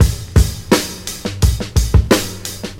• 86 Bpm Drum Groove C# Key.wav
Free breakbeat - kick tuned to the C# note. Loudest frequency: 1773Hz
86-bpm-drum-groove-c-sharp-key-Q1z.wav